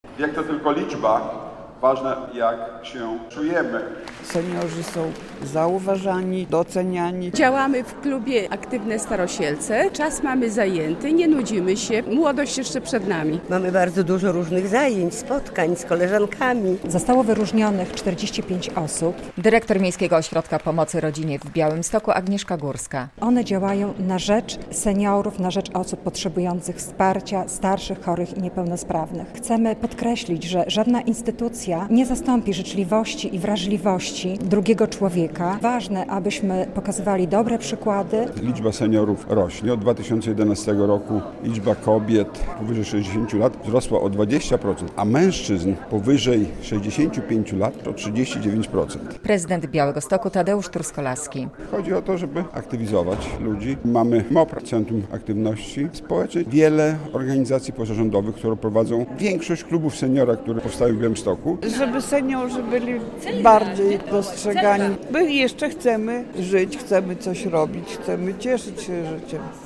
Seniorzy, którzy działają na rzecz innych, otrzymali specjalne podziękowania od prezydenta Białegostoku. Nagrodzono ich podczas uroczystości w Pałacu Branickich z okazji Miejskiego Dnia Seniora.
Miejski Dzień Seniora - relacja